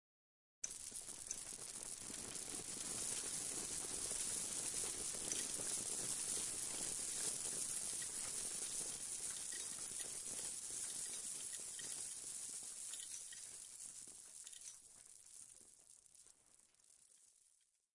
描述：I first created a sound, then added reverb and integrated echo for more resonance and an alternation of accelerating and slowdown tempo with a fade at the end.
标签： fall Rain watter drop